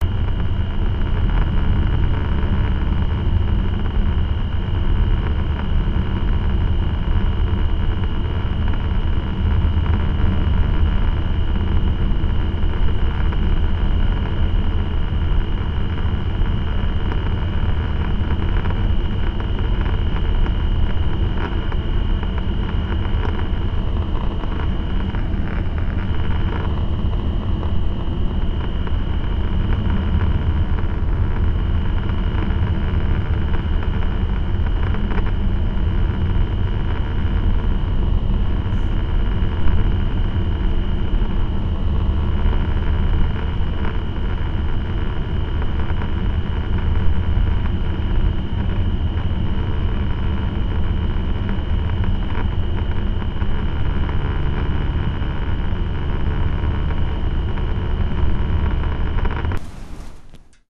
I'm using sound samples of radio static that I recorded last June while on the island of Gotland in southern Sweden.
Static1-0.wav